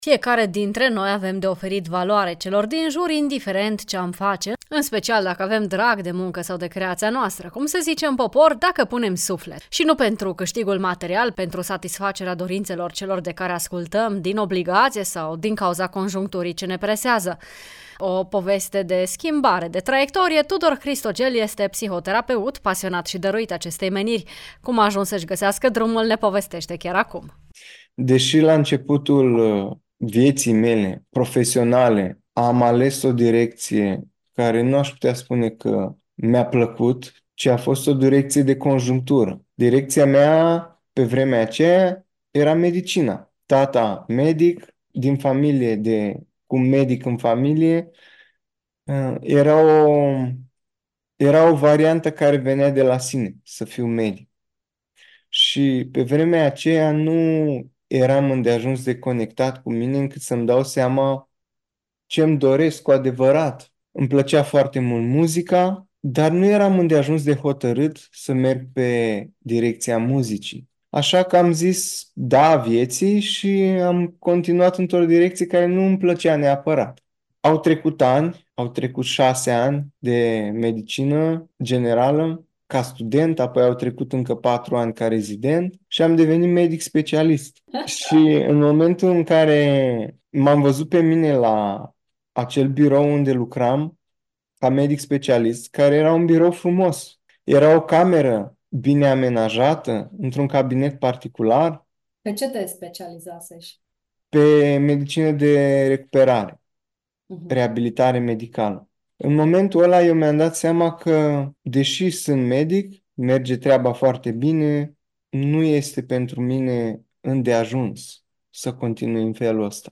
ne aduce în ediția de azi discuția cu un psihoterapeut ce trăiește zi de zi bucuria de a fi, pentru că a găsit puterea și curajul de a face alegerea potrivită pentru viața sa.